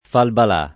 falbalà [ falbal #+ ] → falpalà